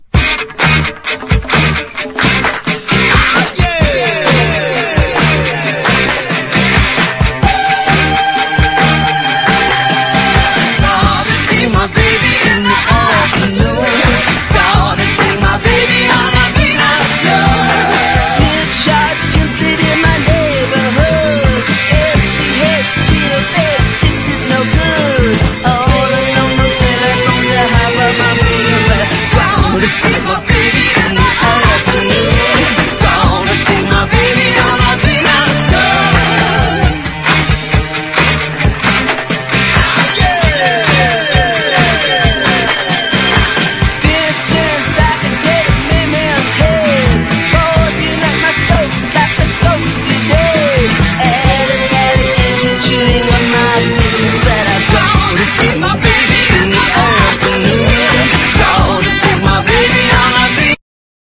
ROCK / PUNK / 80'S～ / NEW WAVE / POST PUNK
オブスキュアなパンク/ニューウェイヴ/パワーポップ/ポスト・パンク/ガレージ音源！